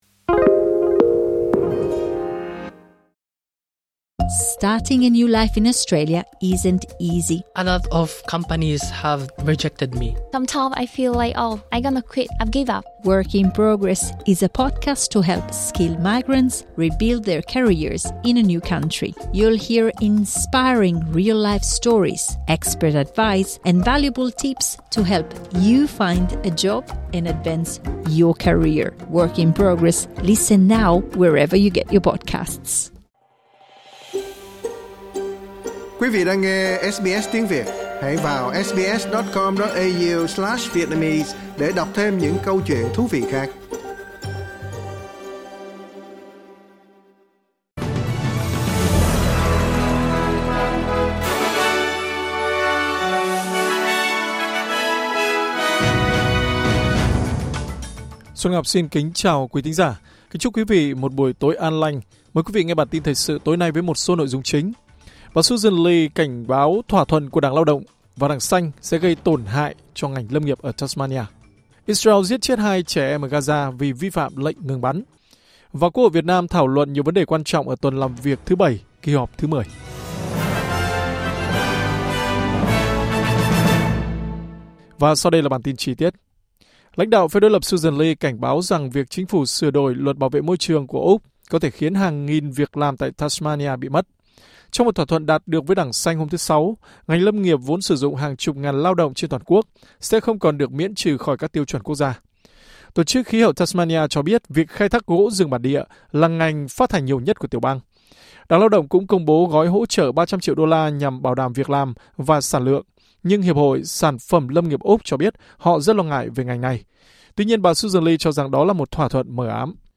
Bản tin của SBS Tiếng Việt sẽ có những nội dung chính.